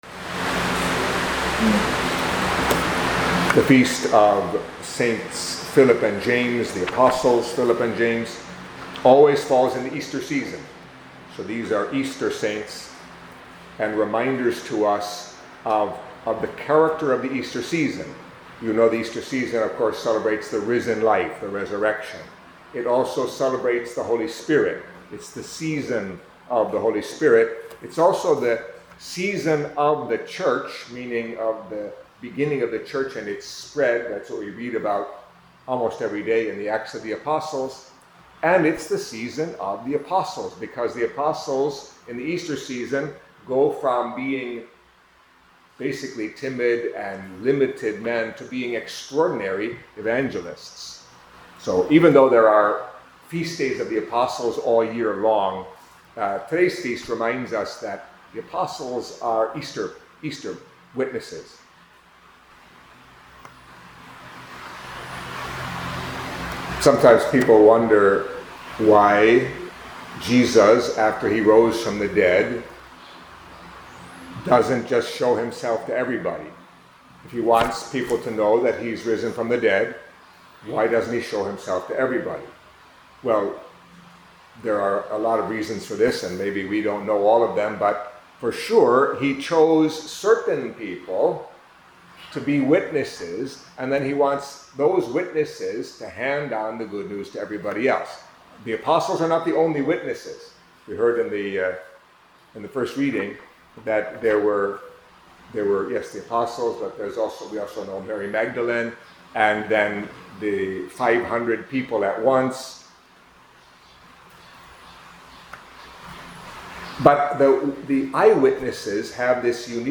Catholic Mass homily for Saints Philip and James